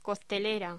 Locución: Coctelera
voz